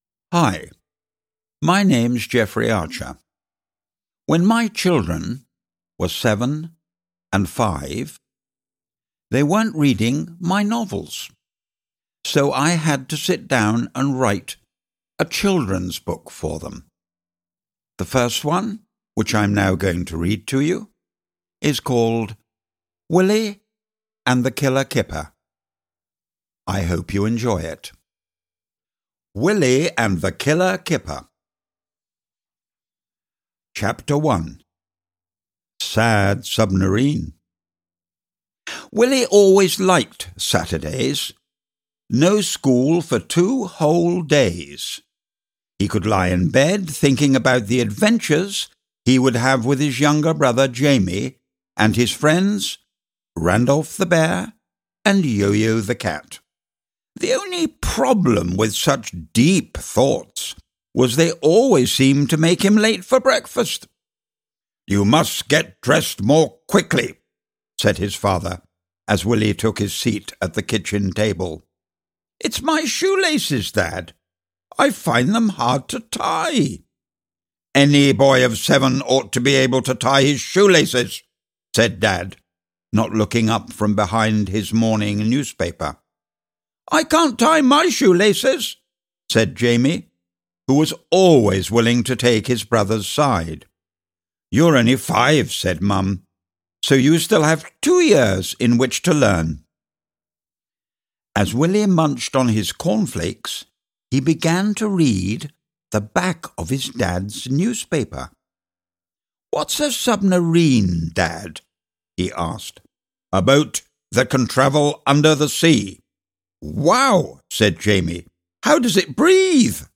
Ukázka z knihy
• InterpretJeffrey Archer